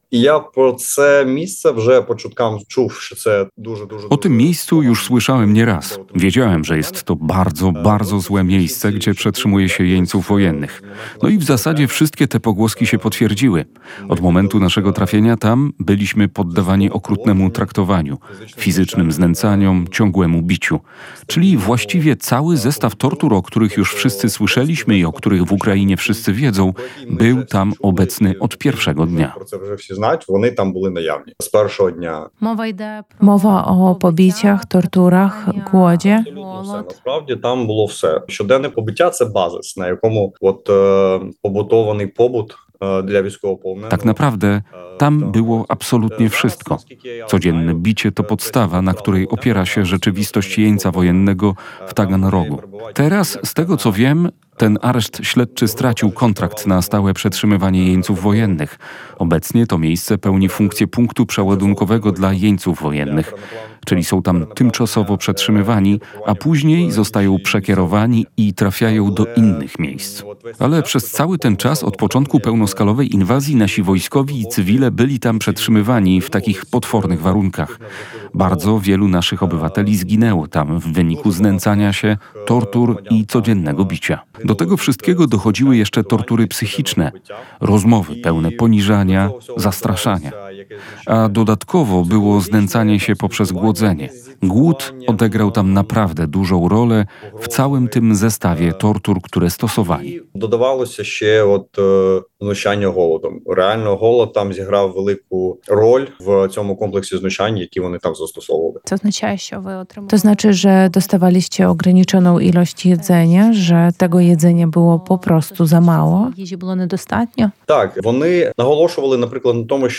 Tortury, codzienne bicie oraz głód – tak wygląda rzeczywistość ukraińskich żołnierzy w rosyjskiej niewoli. Naszym gościem dziś jest żołnierz Zbrojnych Sił Ukrainy, obrońca Mariupola, osoba, która w maju 2022 roku na rozkaz dowództwa wojskowego po ciężkich, zaklętych walkach opuściła zakłady Azowstal i trafiła do niewoli.